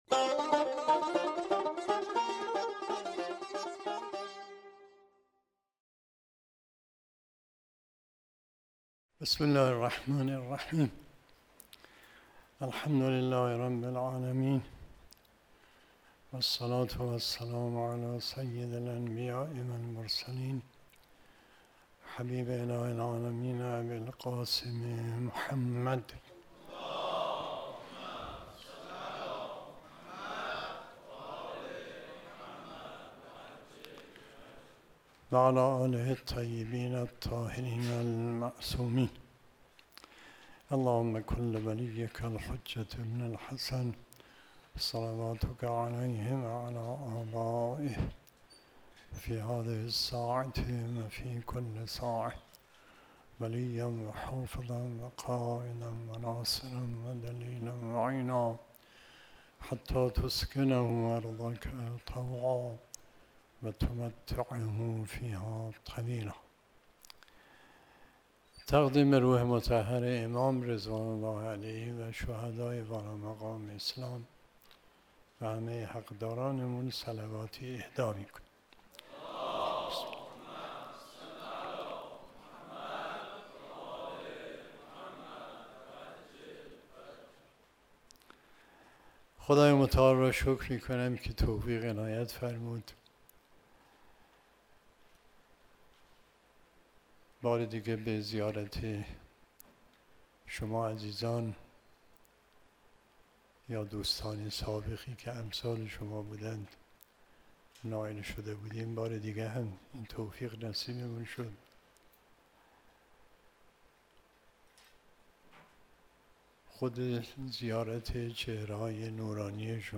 سخنرانی آیت الله علامه مصباح یزدی | دومین اجلاس رابطان جامعه ایمانی مشعر | چهارشنبه 13 تیرماه 1397 - شهر مقدس قم، مجتمع یاوران مهدی (عج)